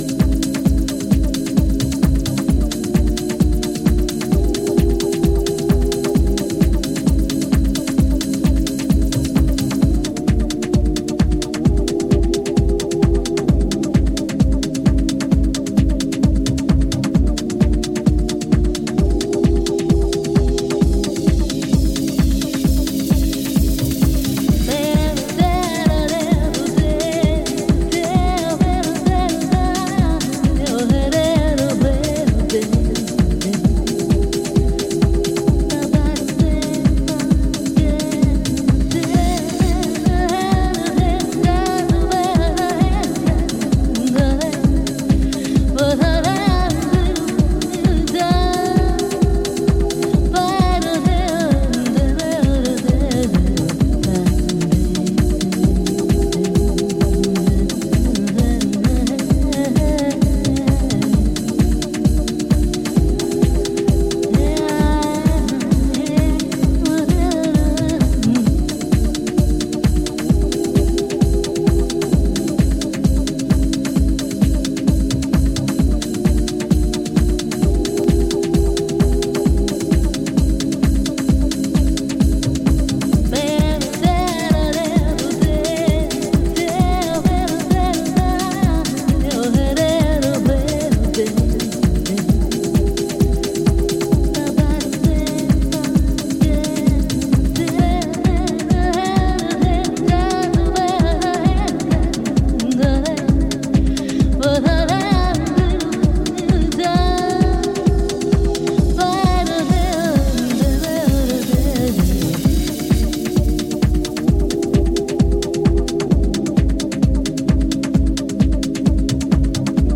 Detroit / Vocal-Deep